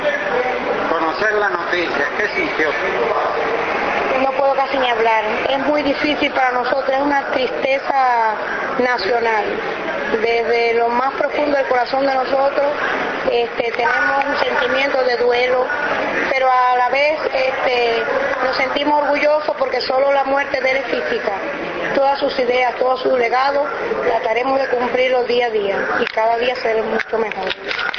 temen formular declaraciones, porque tienen la voz quebrada, pero deciden hacerlo sin necesidad de insistirles.